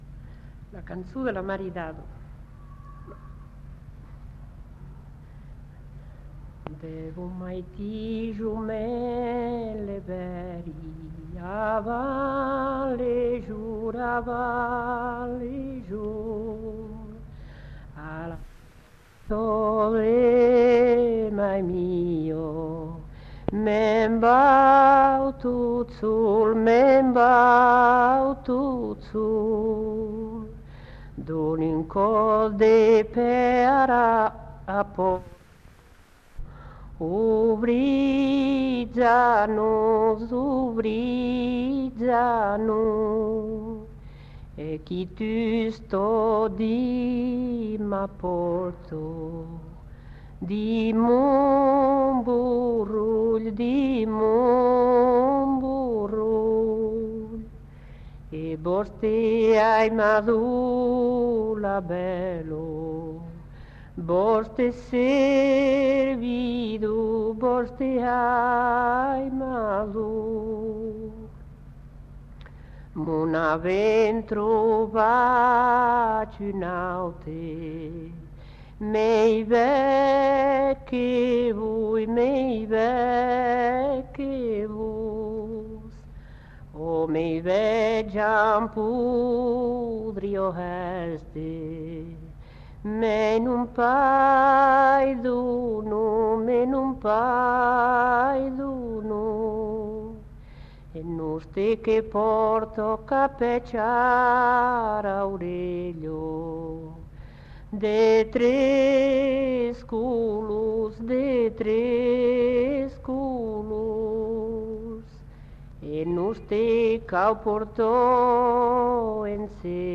Aire culturelle : Couserans
Genre : chant